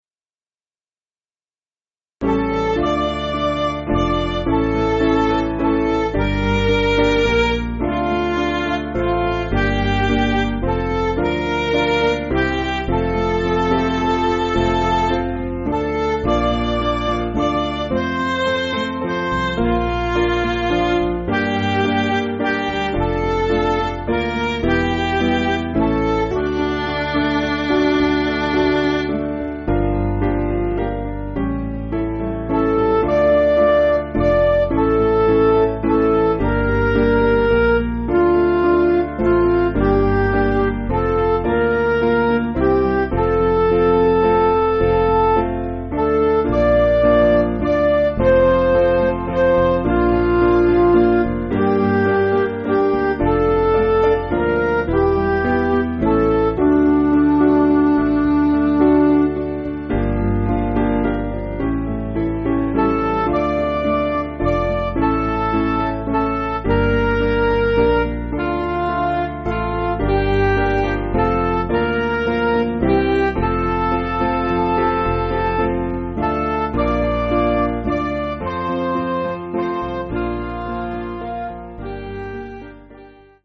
Small Band
(CM)   9/Dm
(Slow)   485.5kb